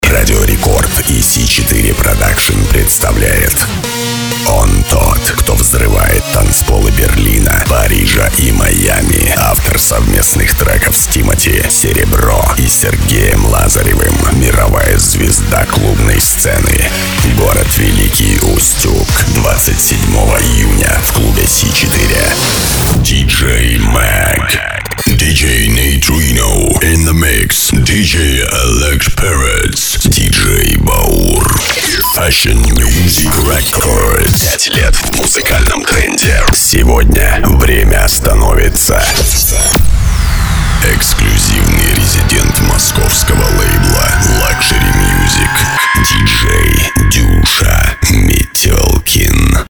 Тракт: Rft Veb Mikrofontechnik Gefell – M-Audio FireWire 410 – Pop Filter Rode